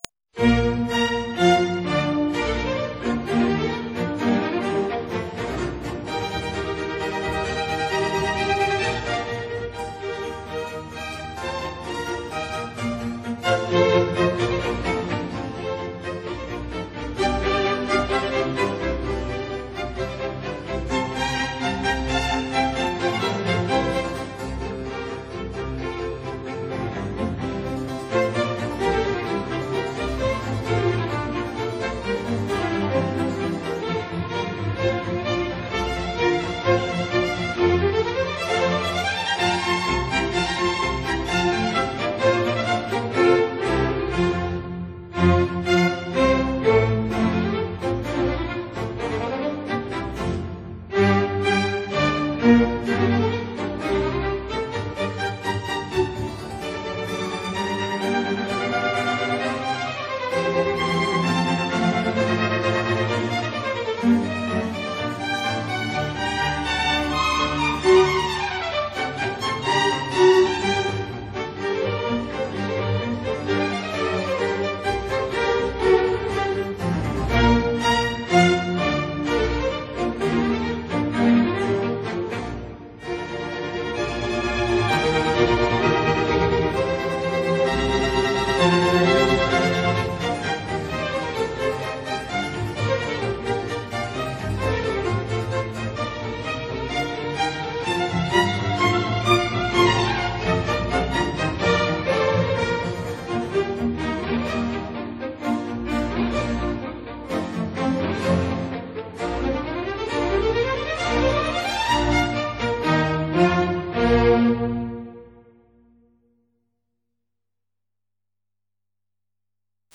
Andante    [0:02:08.63]
Presto    [0:02:24.13]